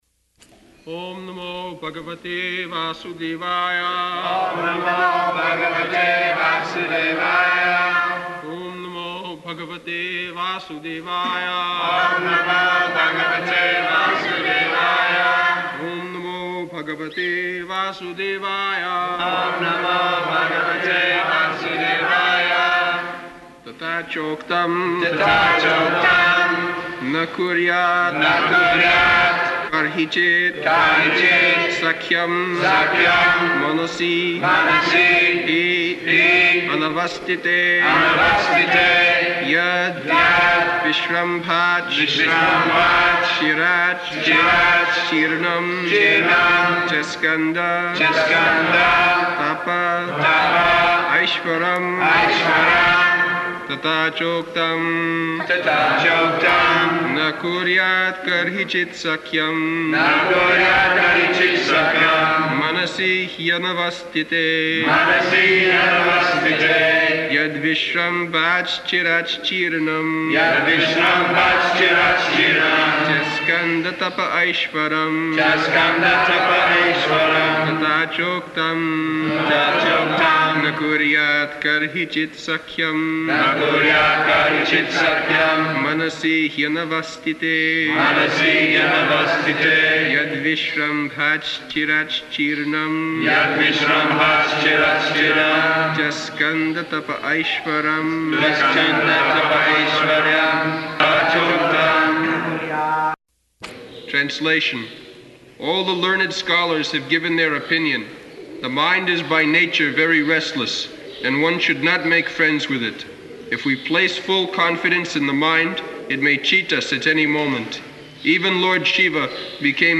November 25th 1976 Location: Vṛndāvana Audio file
[devotees repeat]